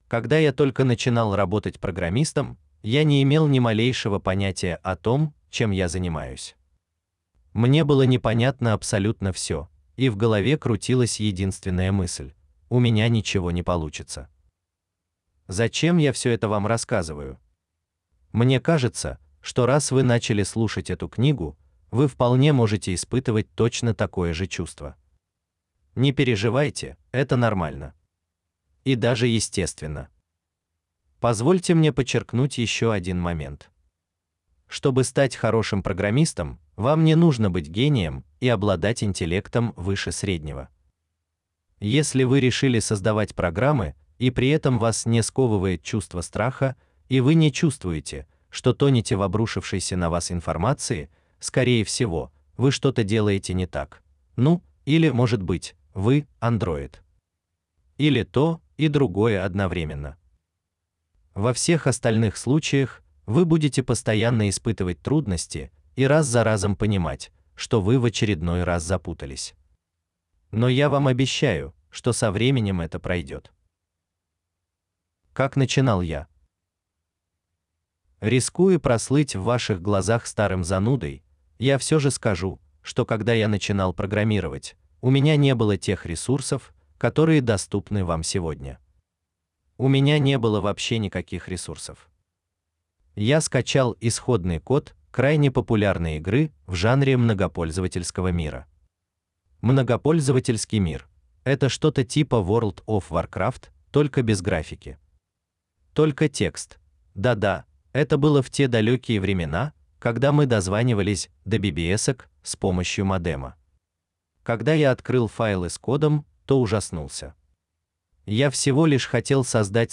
В оформлении аудиокниги 2 музыкальные композиции с сайта Freesound: